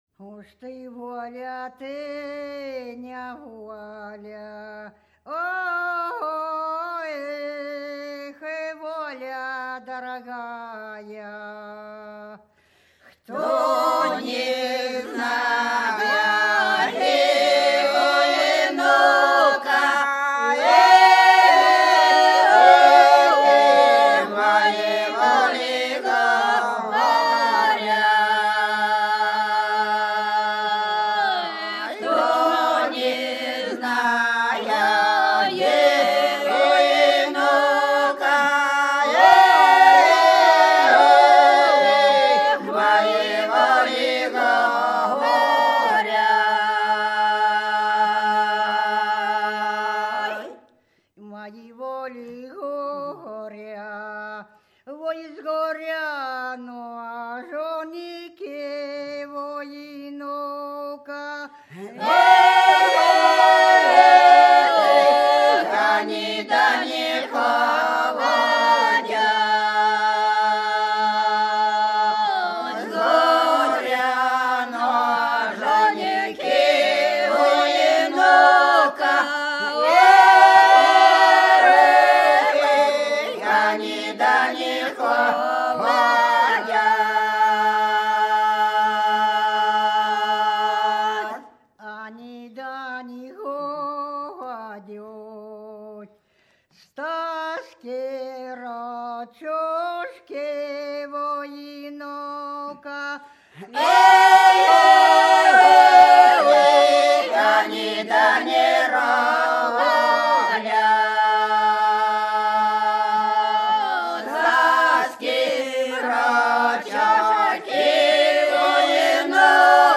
Вдоль по улице пройду (Бутырки Репьёвка) 06. Уж ты воля, ты неволя — протяжная.
(запевала)
(подголосок)